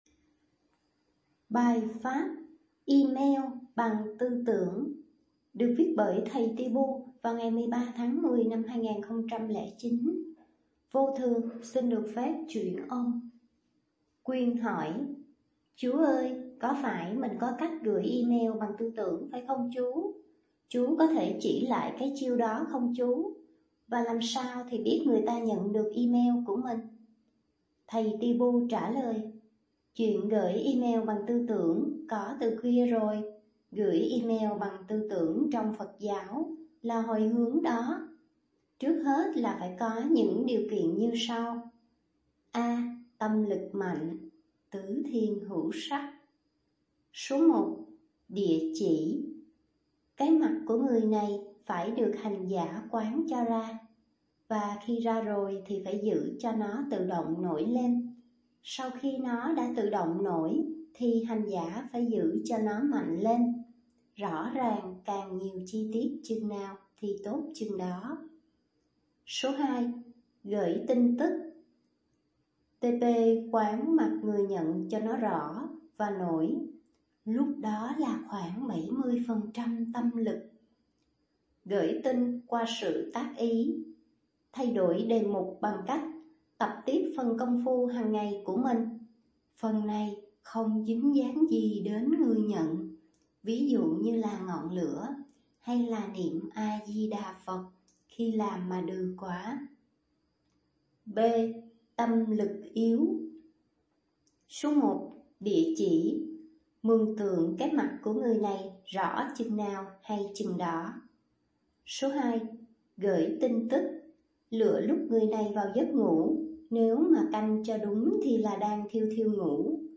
chuyển âm